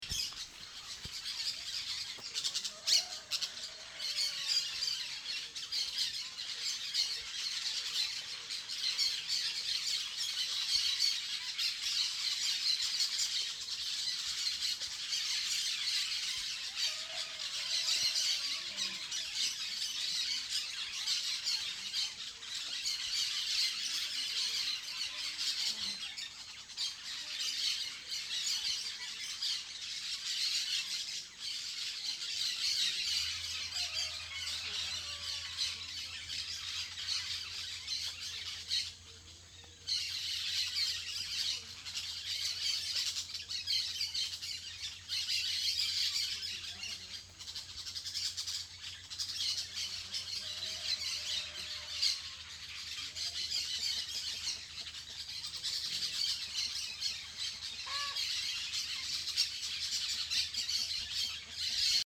28/07/2014 15:00 La nature est luxuriante, les singes sautent entre les bambous, les tatous creusent des terrier, les fourmis suivent leur éternelle heure de pointe, les colibris butinent, les oiseaux chantent et un cheval s'est perdu.